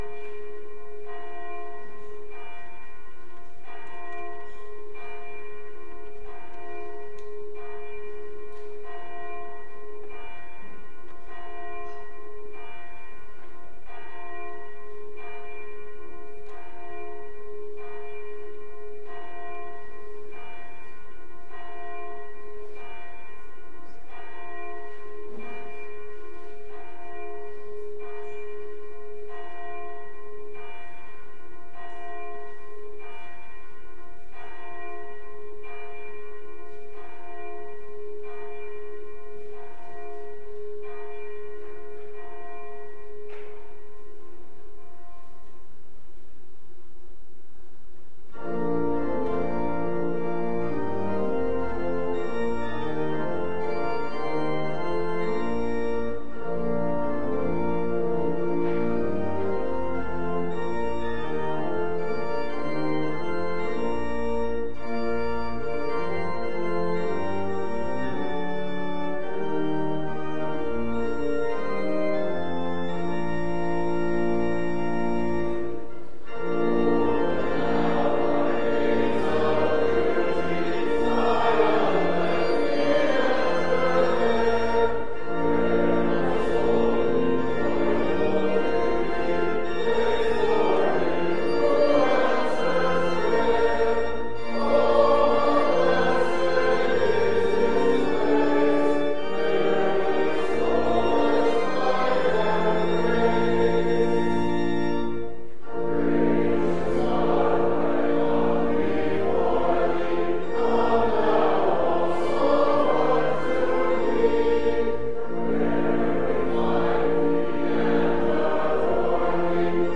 LCOS Worship Service